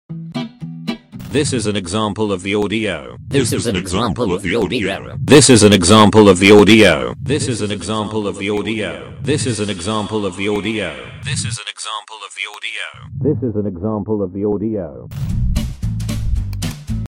Premiere Pro Meme Audio Effects sound effects free download